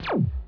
shoot.wav